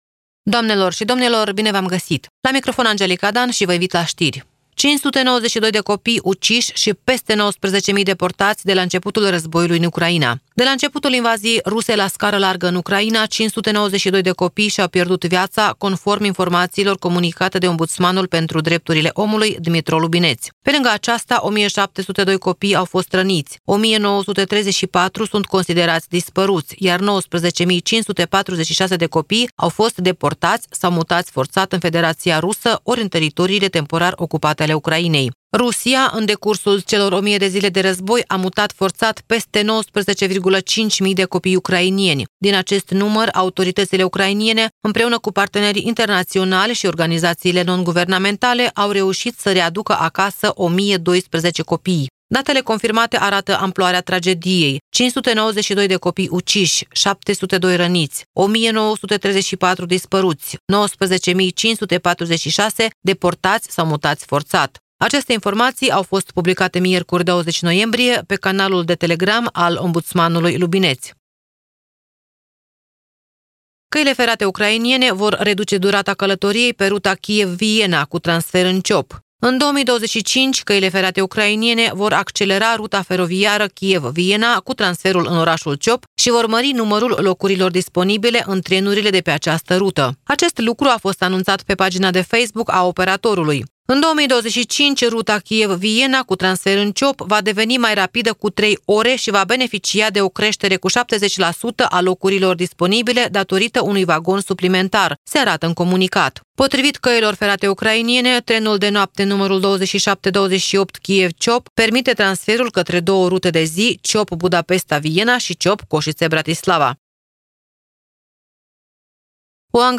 Știri Radio Ujgorod – 22.11.2024, ediția de seară